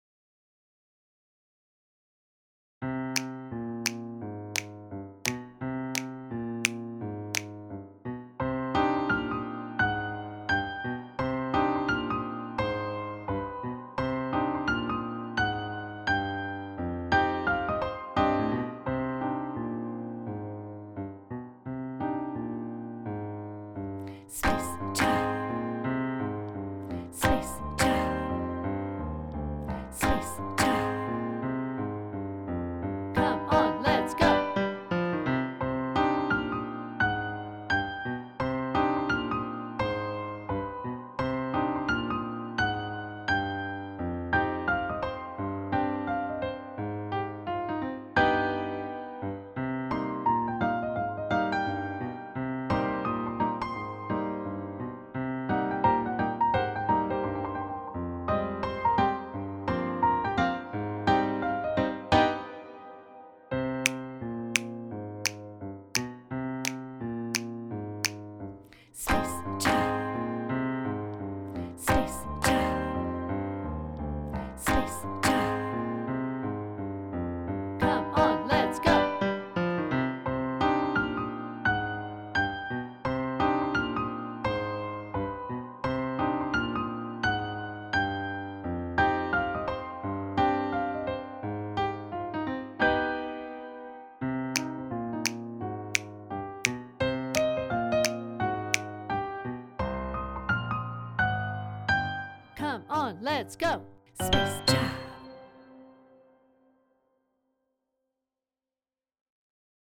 Key: C Blues scale
Time Signature: 4/4 (BPM ≈ 146–178)